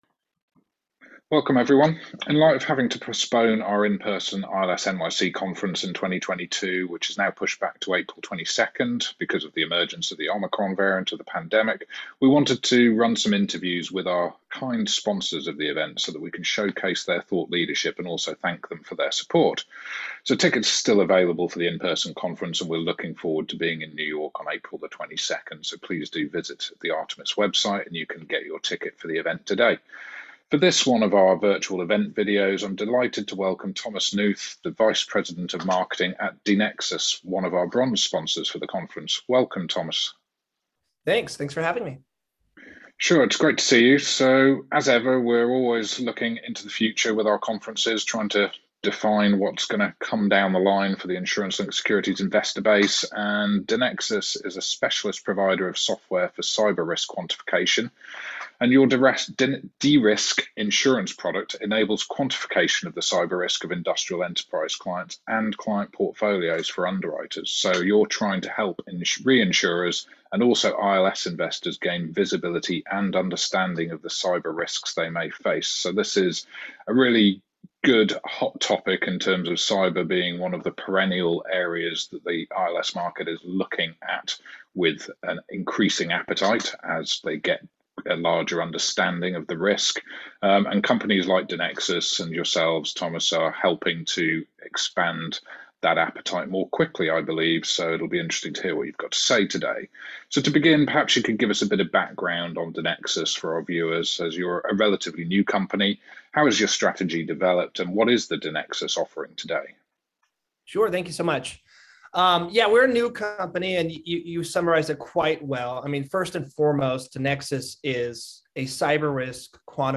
Artemis ILS NYC 2022 interview